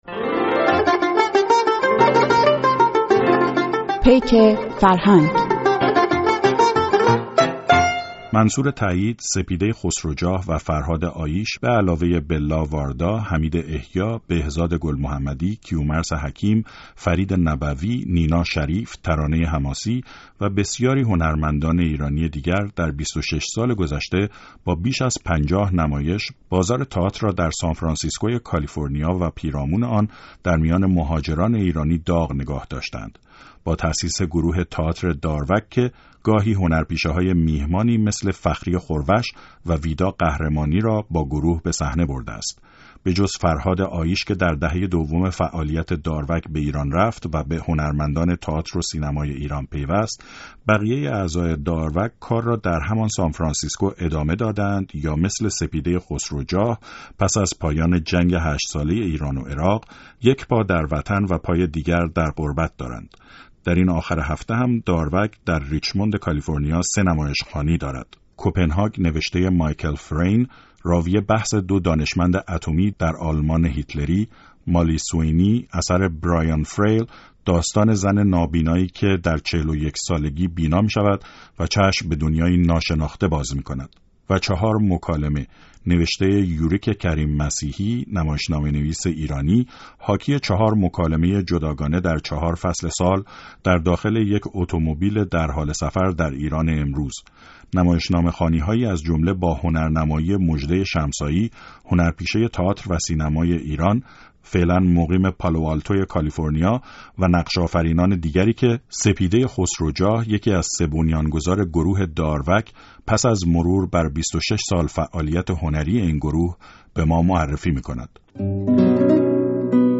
گفت‌وگو با یکی از اعضای گروه داروگ را بشنوید